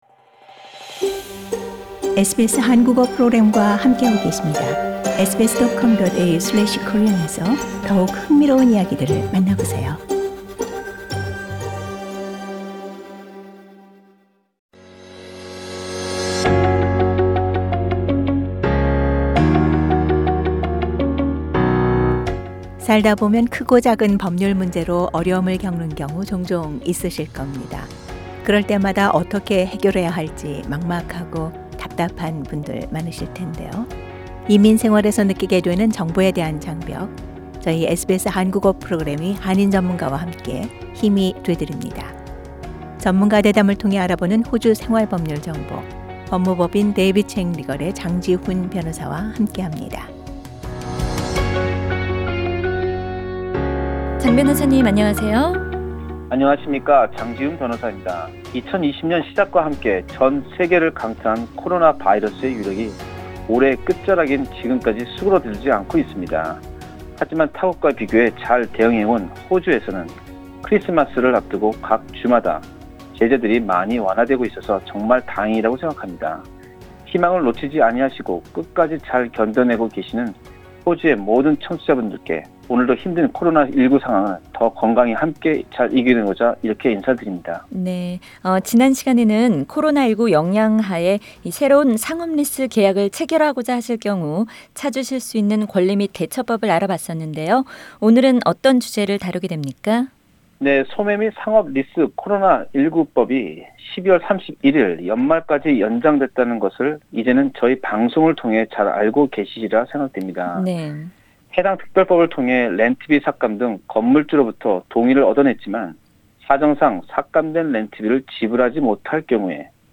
전문가 대담을 통해 알아보는 호주 생활법률정보